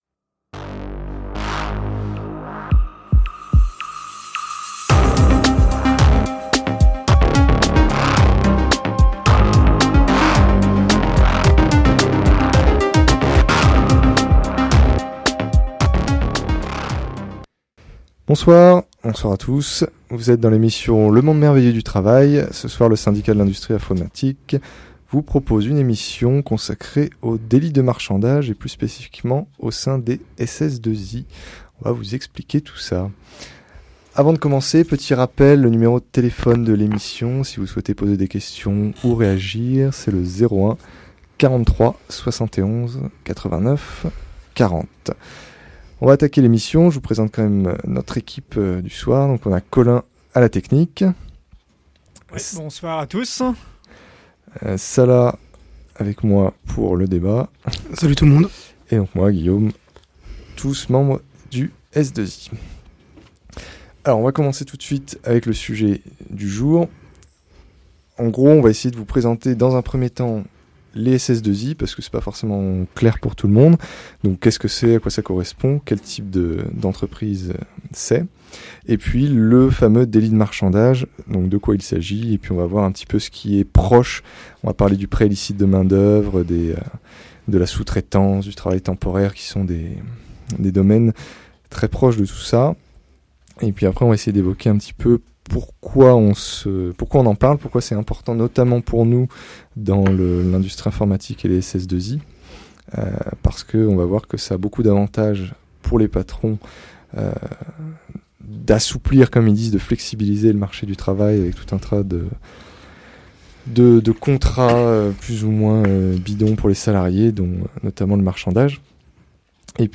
L’émission de radio que nous animons tous les premiers lundi du mois de 19h30 à 21h sur Radio Libertaire (89,4Mhz FM en région parisienne ou en ligne) change de nom est devient Les débogueurs du système !
Voici donc l’émission Les débogueurs du système du 3 juin 2013 sur Radio Libertaire, animée par le Syndicat de l’industrie informatique. Le thème de l’émission est le cloud.